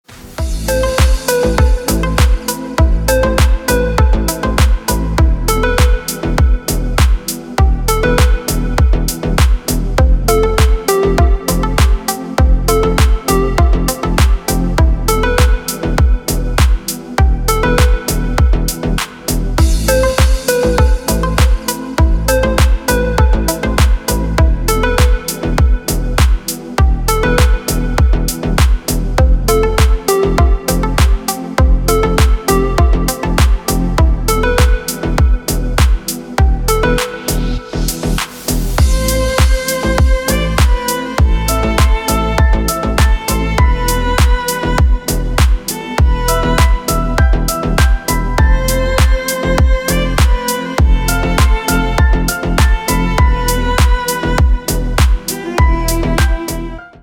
• Качество: Хорошее
• Песня: Рингтон, нарезка